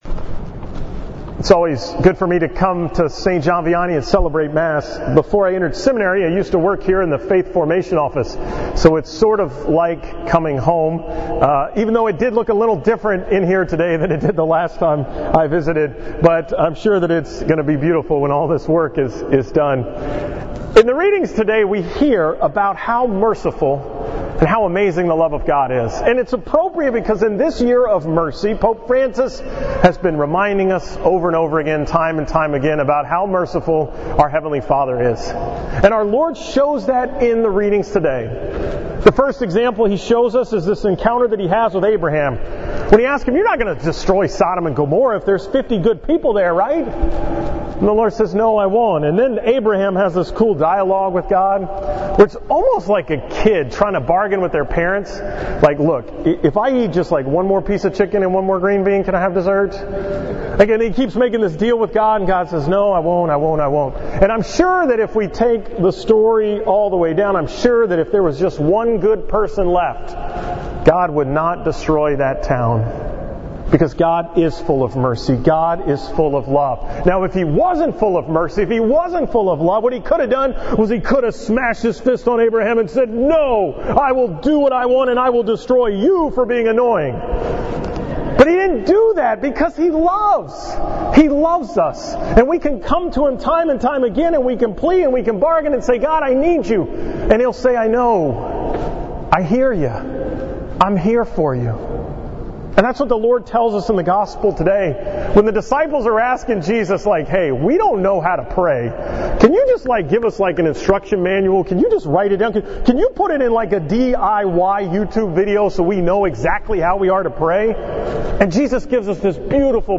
From the 5:30 pm Mass at St. John Vianney on July 24, 2016